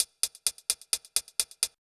TEC Beat - Mix 17.wav